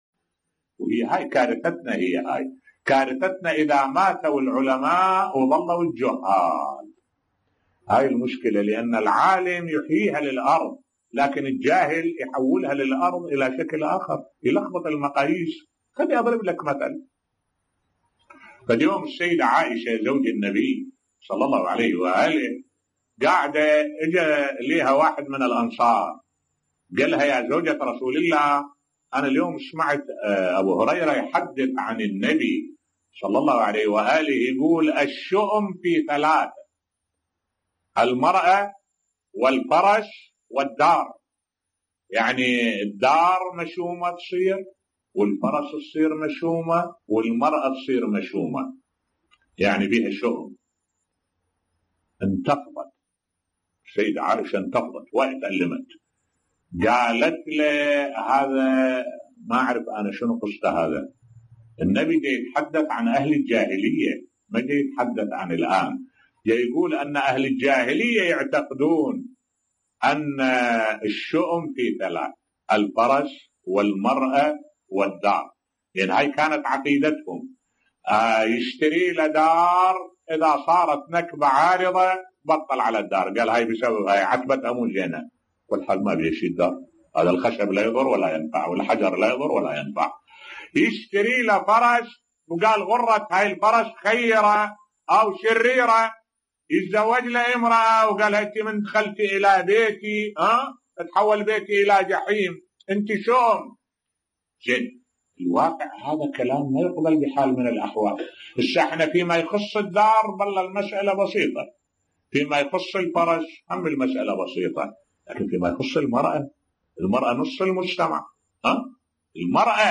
ملف صوتی يدعو لتنقية التراث الاسلامي في نظرته للمرأة بصوت الشيخ الدكتور أحمد الوائلي